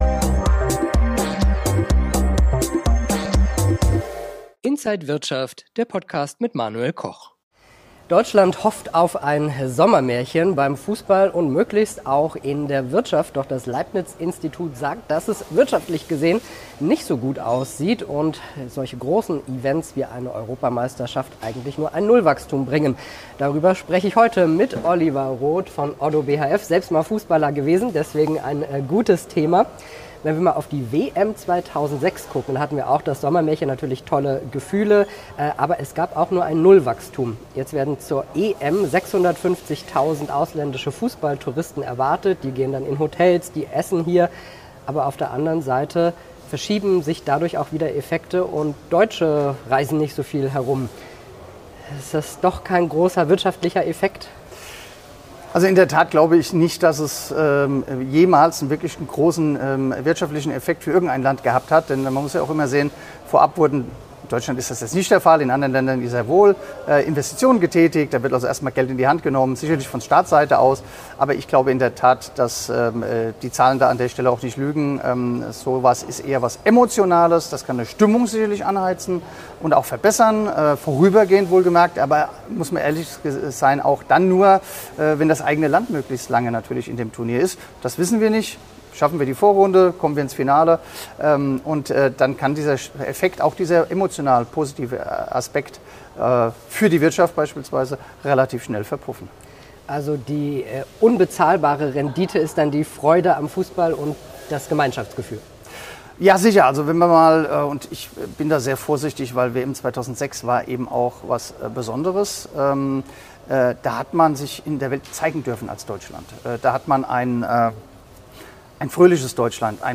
Interview
an der Frankfurter Börse